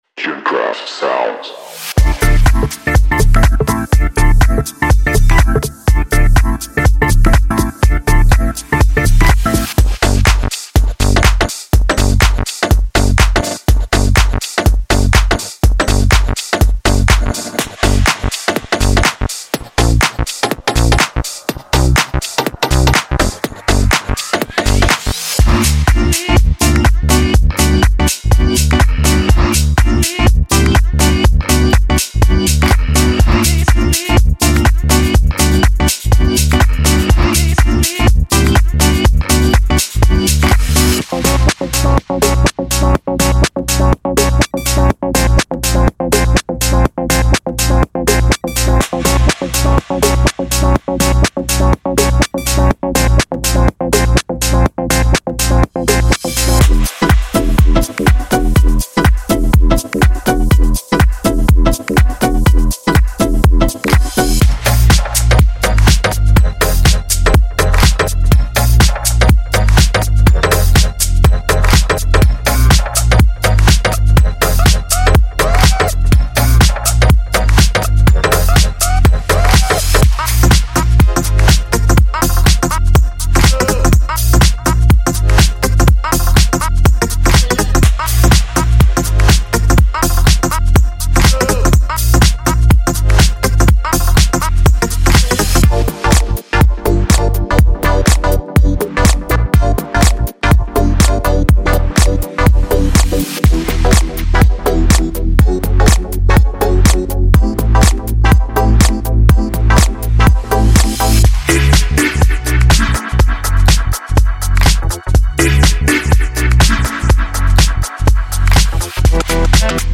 • 70个鼓循环（脚踢，军鼓和鼓掌，Percs，帽子……）
• 210个Synths循环
• 229个低音循环
• 40个和弦循环
• 108个额外循环（音乐，打击垫，主音，弹奏，合成器，人声…）
• 多种类型（房屋，深度，未来，科技屋等）